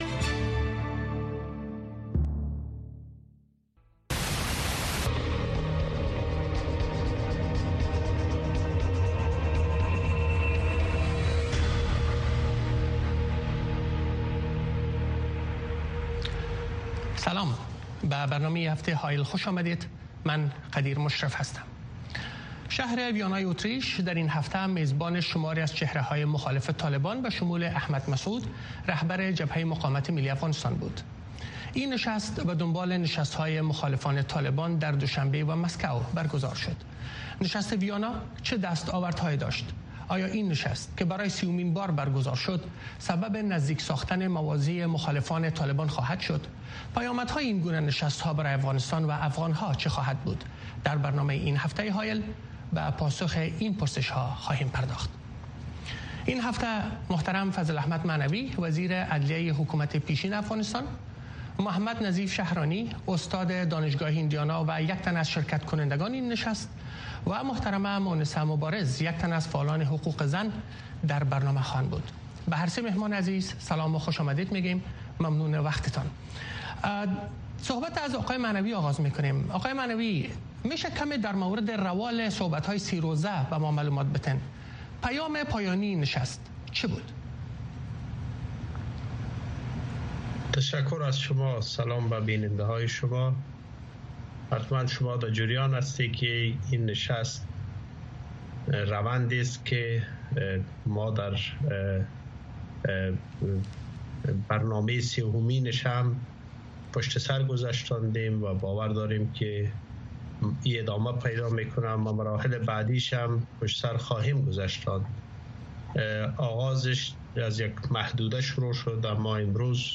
کاوشگری و ژرف نگری رویدادهای داغ روز در افغانستان زیر سلطۀ طالبان را در برنامۀ حایل از صدای امریکا دنبال کنید. وضعیت افغانستان، چگونگی رویدادها، بحث در مورد راه‌حل مشکلات و بن بست‌های موجود در آن کشور از زبان کارشناسان، تحلیلگران و مسوولان هر جمعه شب ساعت ۷:۳۰ به وقت افغانستان